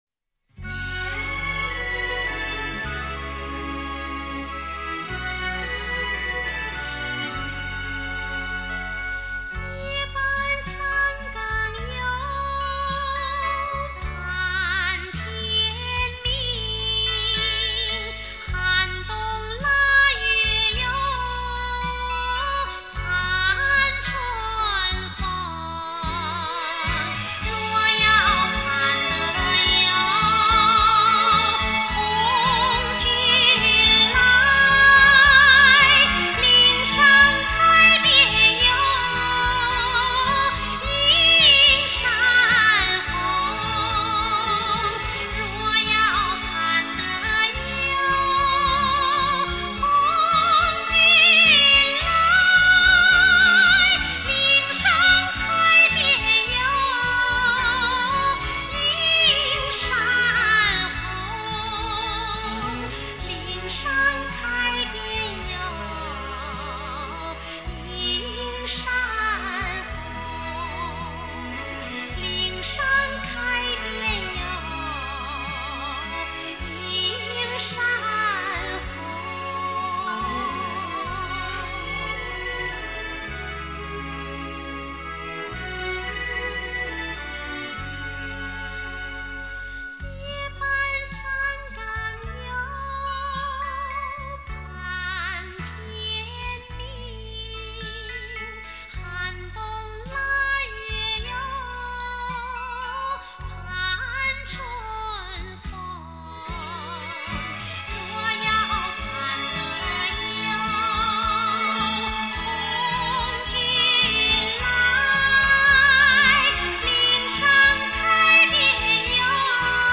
经典电影老歌曲 不同版本的精彩演绎